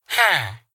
latest / assets / minecraft / sounds / mob / villager / yes1.ogg